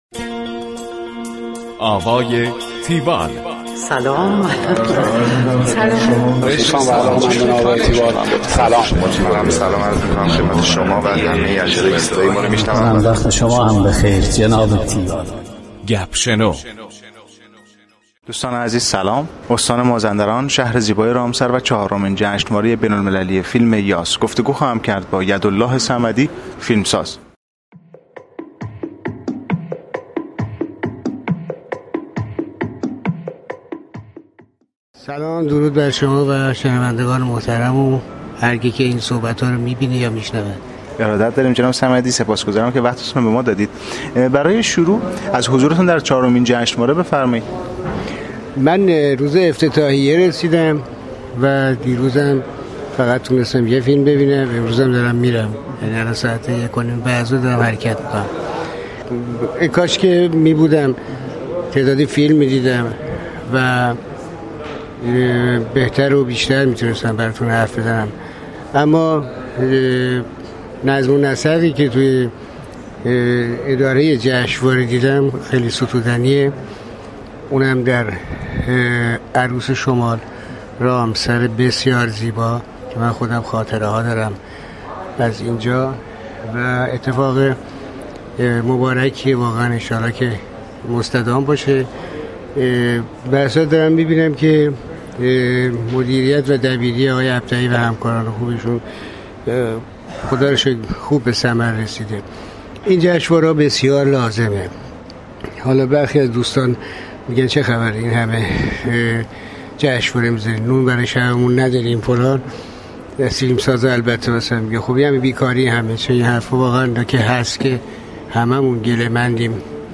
گفتگوی تیوال با یدالله صمدی / فیلمساز.
tiwall-interview-yadollahsamadi.mp3